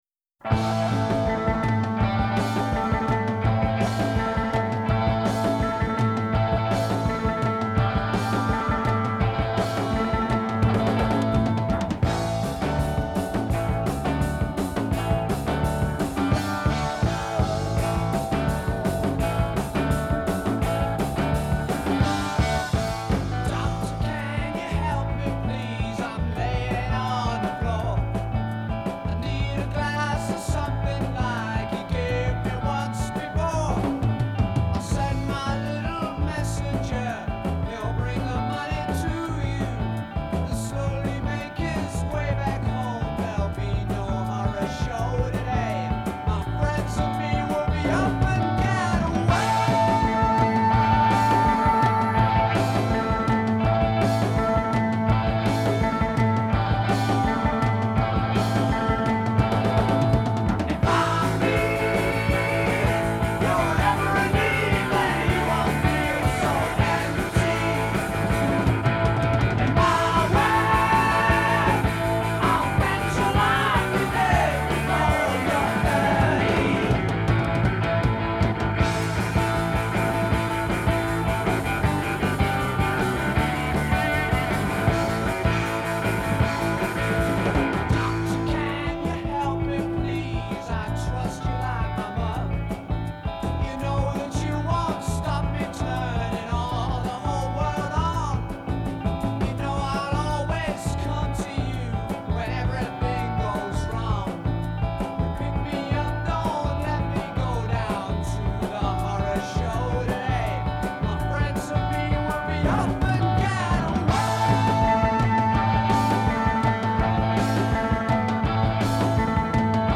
Country: Rock, Hard Rock, Blues Rock